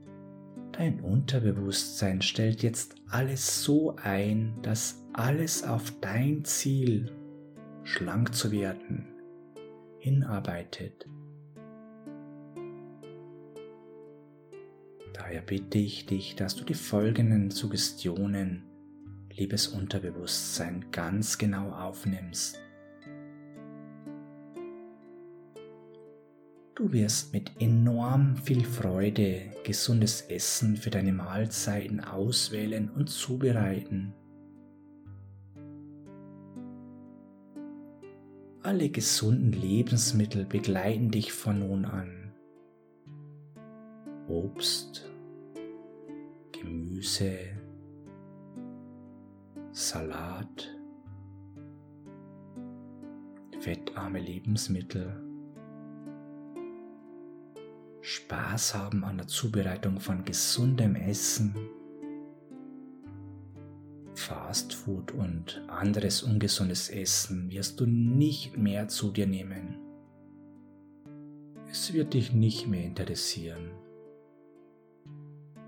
Diese Version beginnt mit einer sog. Blockadenlösung, mit welcher Sie bewusste oder unbewusste Sorgen endlich loslassen können. Mit dieser geführten Hypnose führen wir Sie zunächst an einen Ort in der Natur, an dem Sie erholsame Momente erleben werden. Sie werden an einem wunderschönen Bach entlang wandern, bevor sie an einem großen Ozean ankommen.